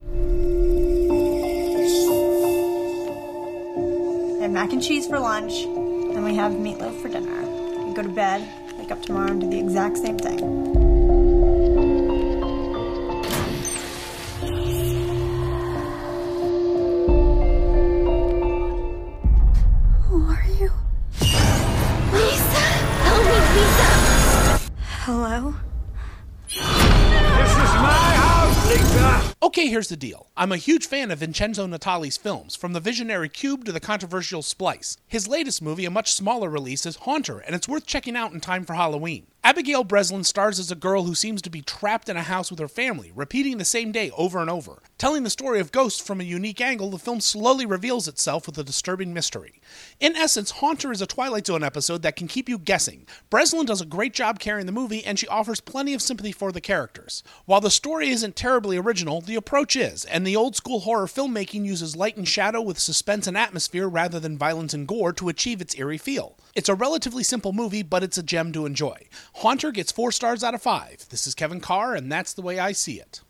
‘Haunter’ Movie Review